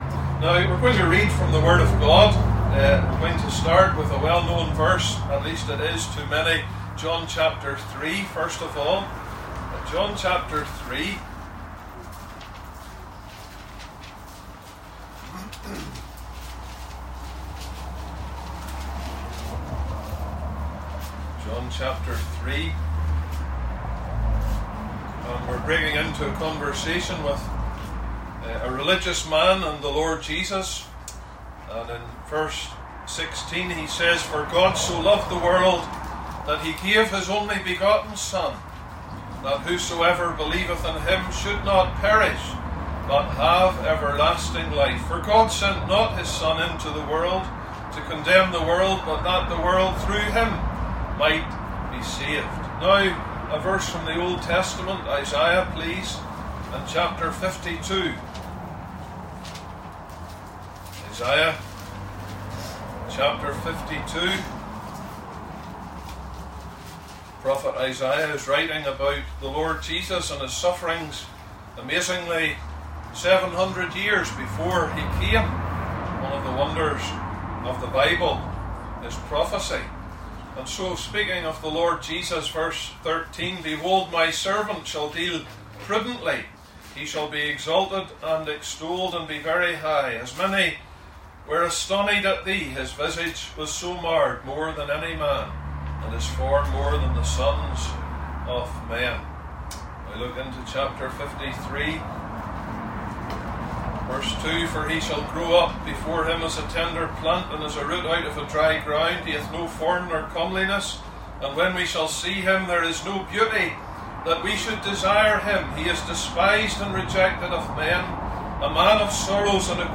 opens the 1st day of Tent meetings
preaches on the various sufferings of the Lord